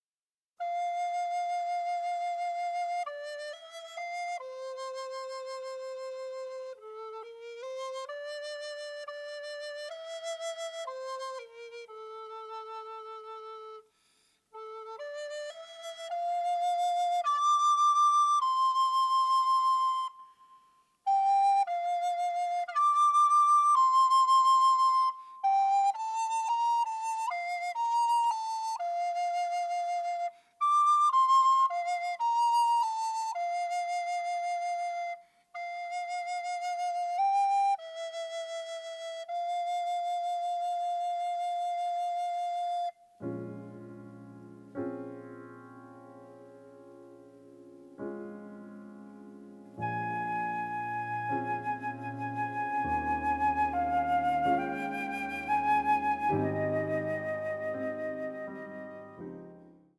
melancholic passages with atonal and dissonant moments